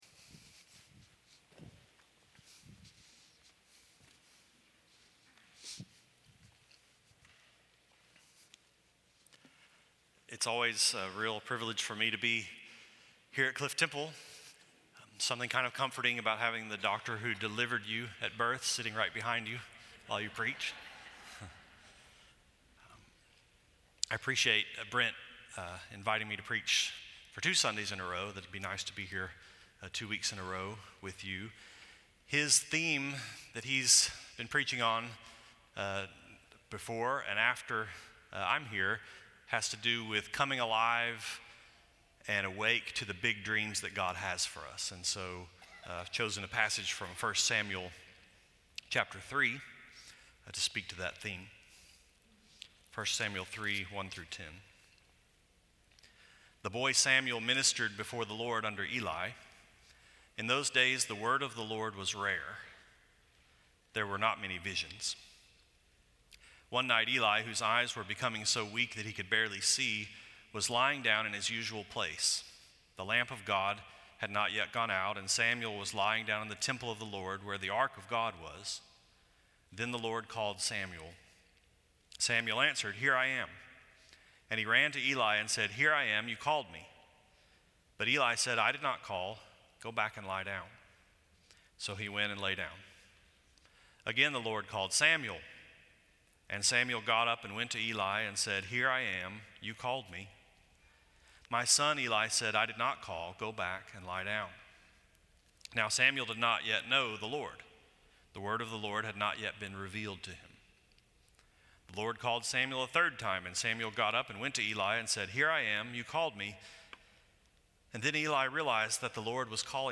This sermon was preached at Cliff Temple Baptist Church in Dallas, Texas on September 7, 2017 Share this: Share on X (Opens in new window) X Share on Facebook (Opens in new window) Facebook Like Loading...